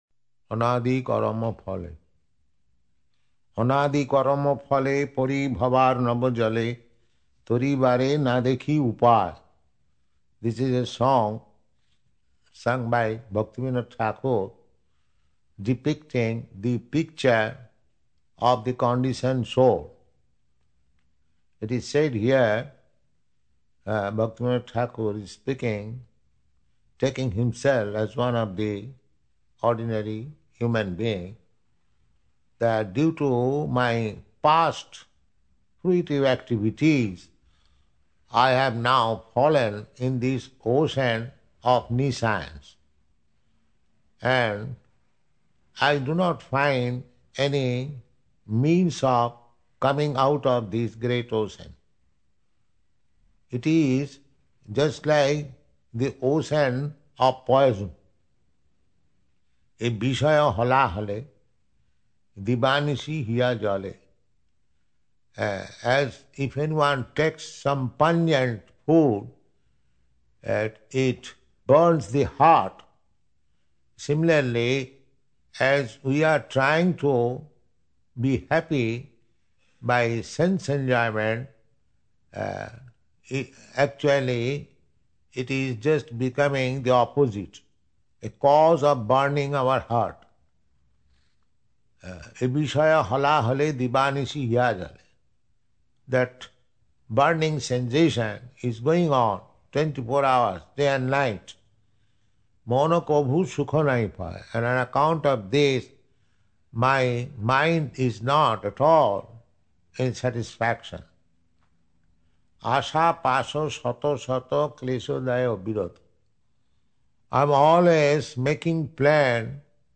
720926 – Anadi Karama Phale and Purport – Los Angeles
Anadi_Karama_Phale_Purport.mp3